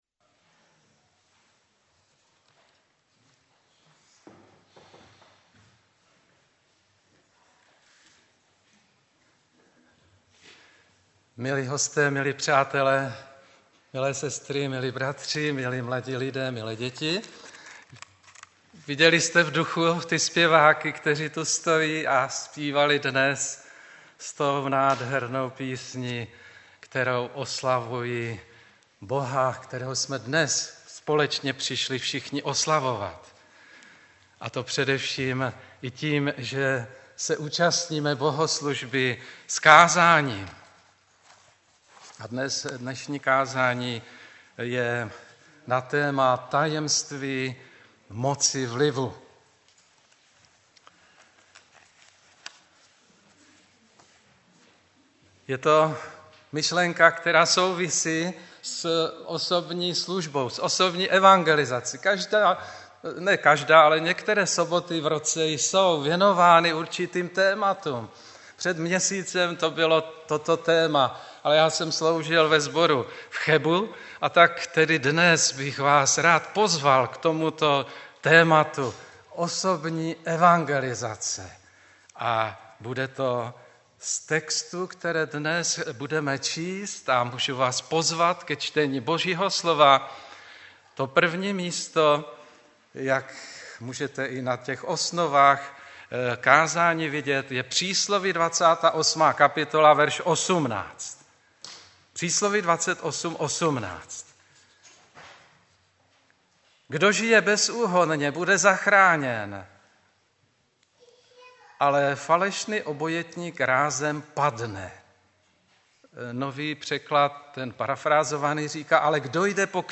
4.3.2017 v 20:30 do rubriky Kázání .